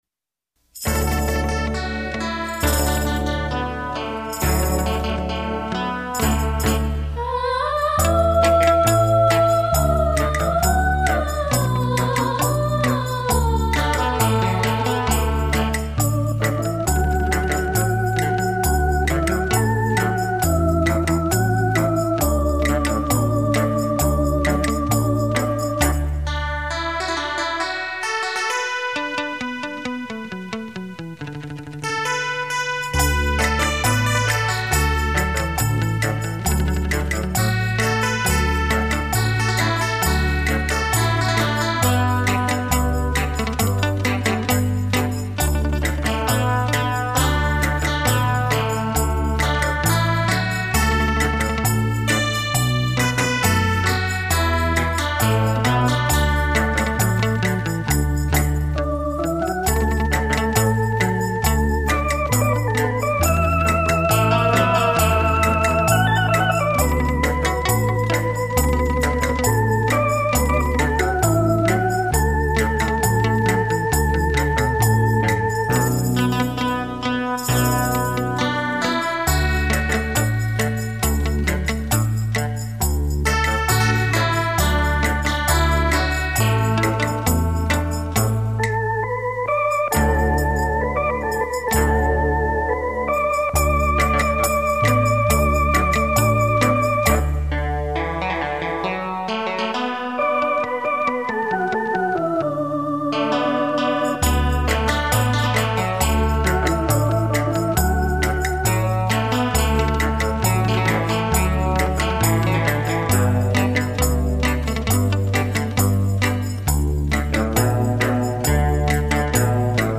早期的音质超好，仅供节日试听欣赏，曲名看图，下载后请删除，不得用于任何商业用途！